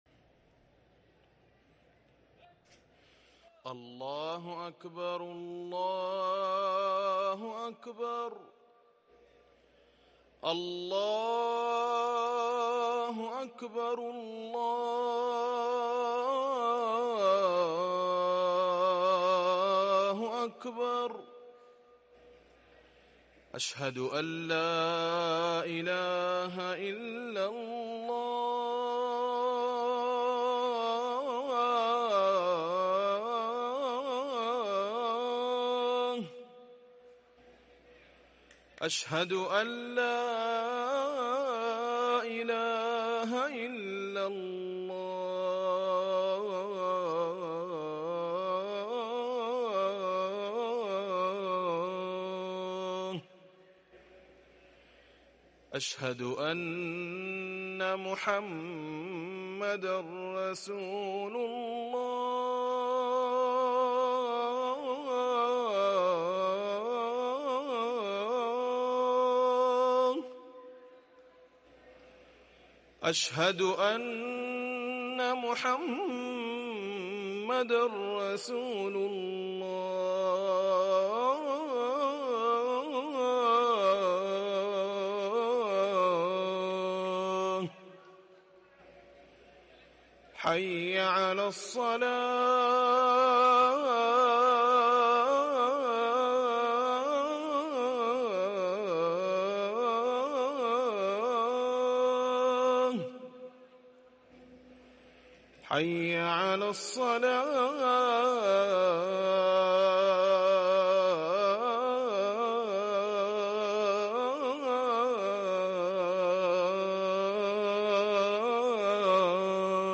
أذان العشاء